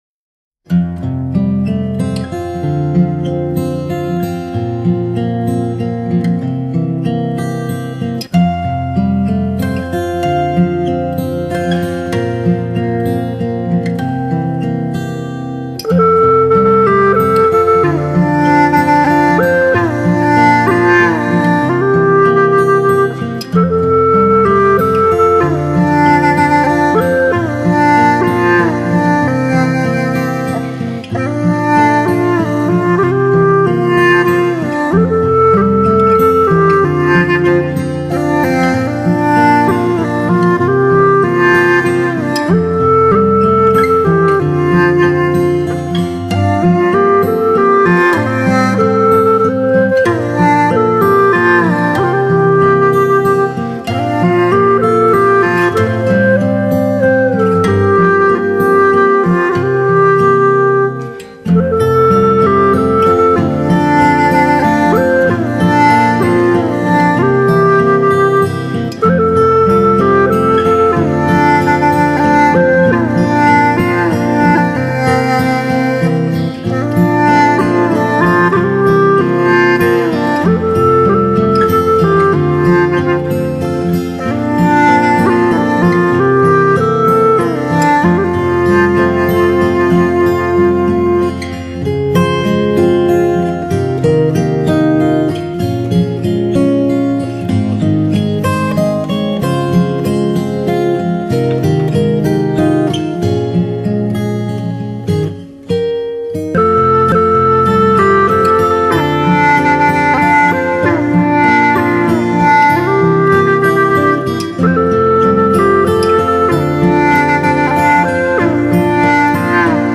第一张自然音乐超级CD
360度声音动态 超立体环绕音场
近百种真实自然声音．最佳环境音响测试片
● 近百种鸟、蛙、蝉、虫、哺乳类、溪流、海洋等日夜间自然物种真实鸣声，